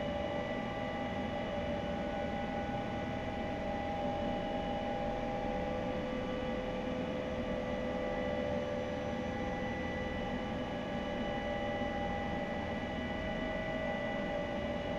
H145_Ground_Idle2-left.wav